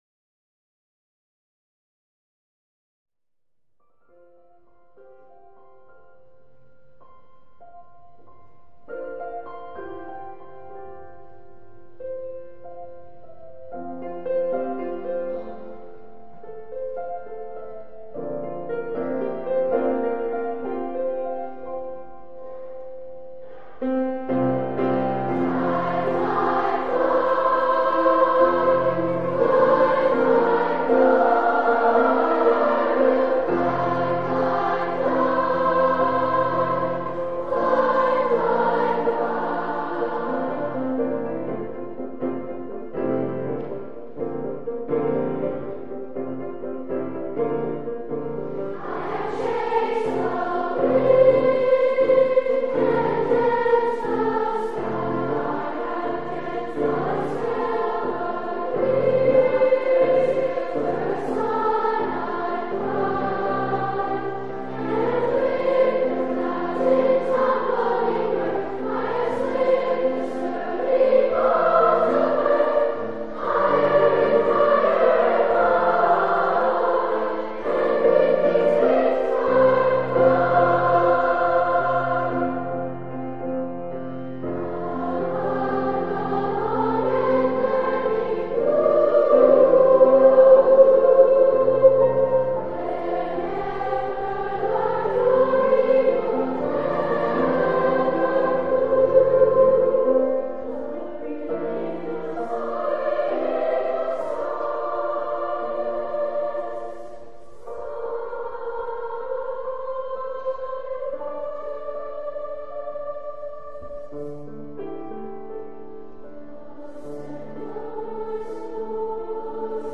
Voicing: "Two-Part (opt SSA)"